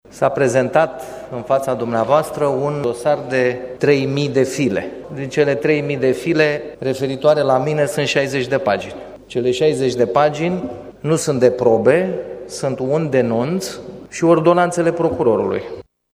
Prezent la şedinţa de plen, Dan Şova şi-a susţinut nevinovăţia şi le-a solicitat colegilor să voteze conform propriei conştiinţe: